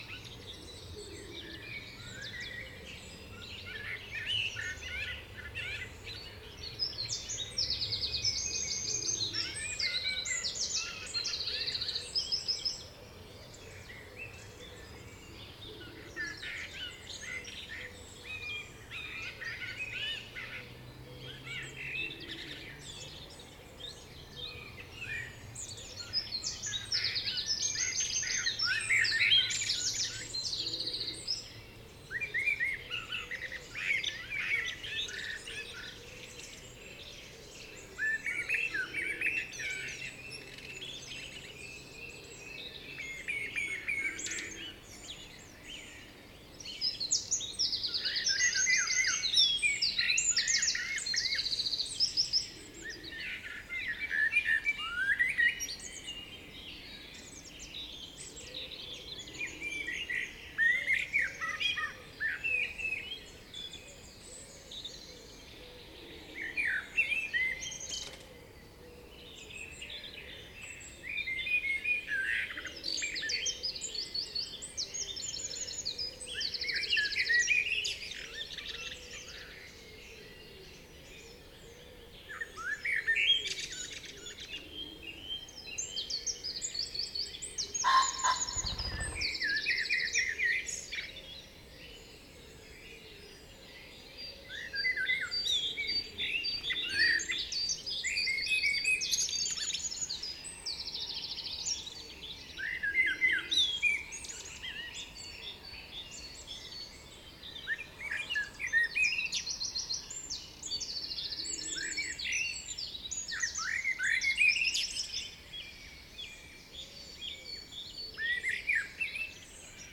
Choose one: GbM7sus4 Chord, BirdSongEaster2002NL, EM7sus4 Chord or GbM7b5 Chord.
BirdSongEaster2002NL